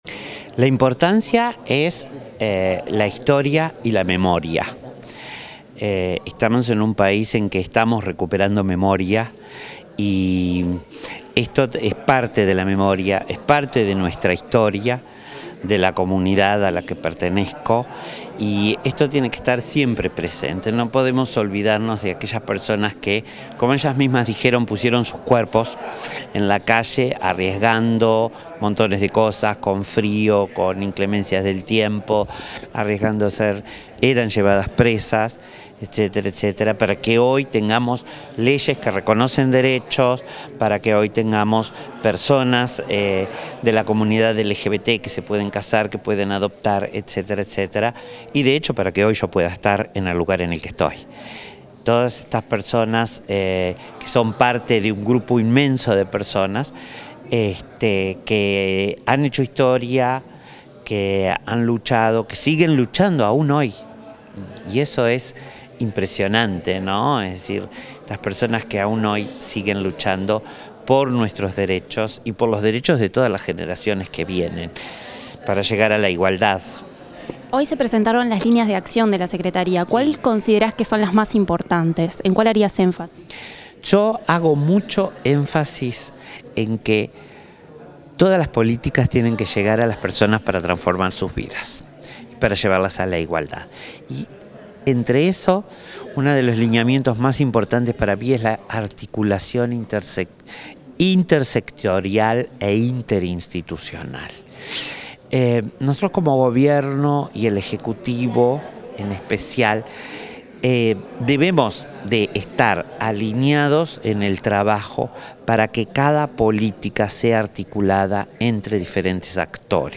Declaraciones de la secretaria de Derechos Humanos de la Presidencia, Collette Spinetti
En el marco de la celebración del Día Internacional del Orgullo LGBTIQ+, la Secretaría de Derechos Humanos de la Presidencia de la República presentó sus líneas de acción y realizó un homenaje a tres activistas. Al finalizar la actividad, la titular de la dependencia, Collette Spinetti, se expresó acerca de la jornada.